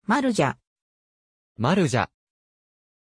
Aussprache von Maarja
pronunciation-maarja-ja.mp3